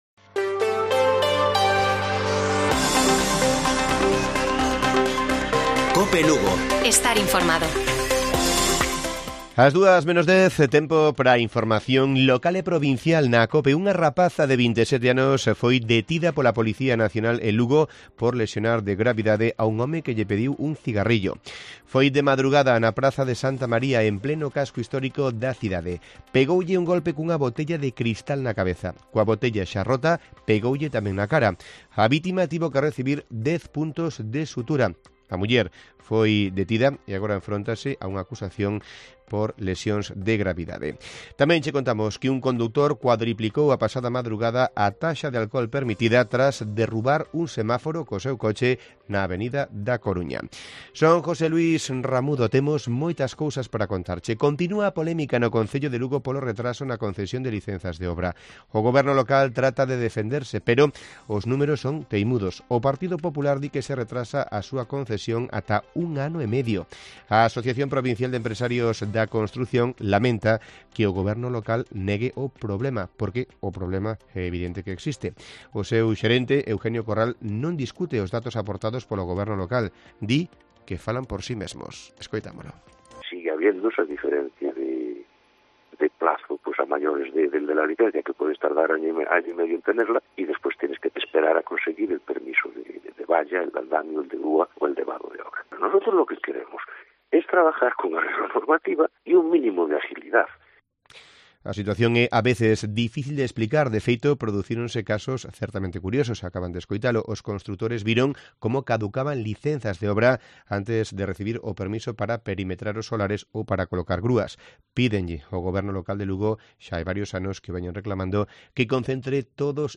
Informativo Mediodía de Cope Lugo. 10 de marzo. 13:50 horas